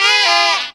HARM FALL 2.wav